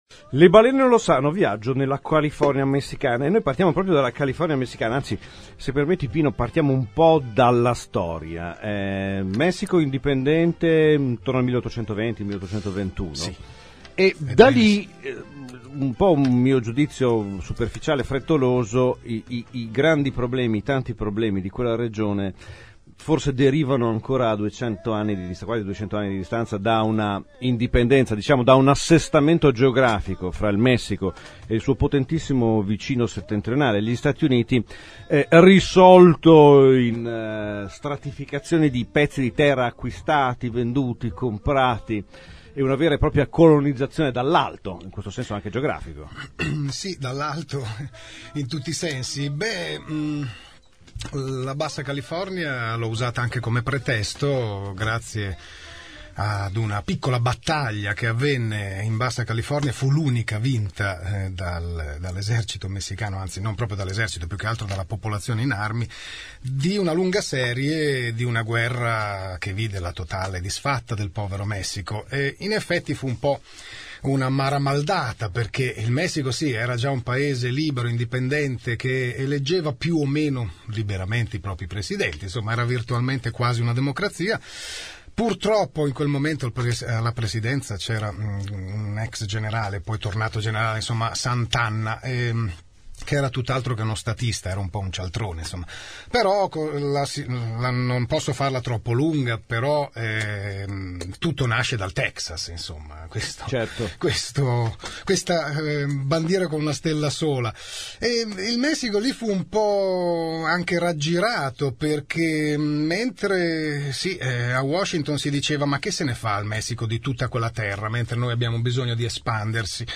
Pino Cacucci presenta in un'intervista il suo ultimo libro Le Balene lo sanno; un viaggio nella California messicana in compagnia delle balene grigie che scelgono le baie messicane per accoppiarsi e riprodursi.